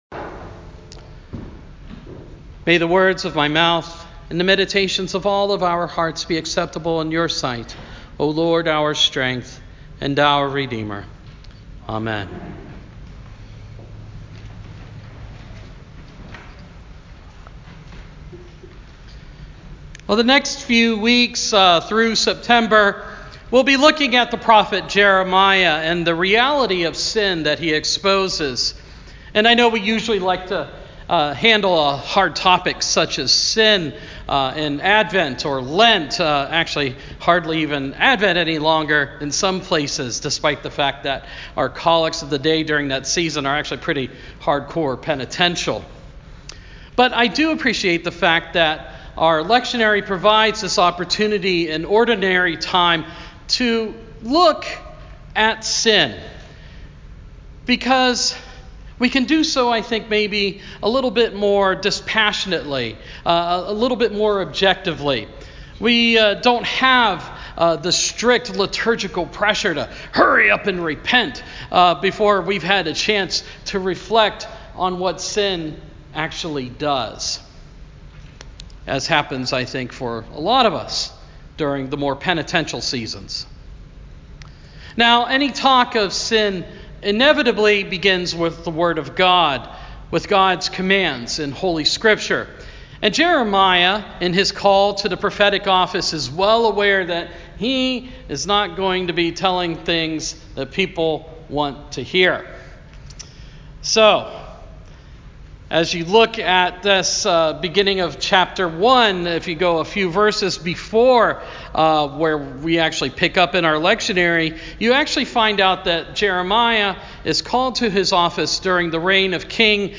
Sermon – Proper 16 – 2019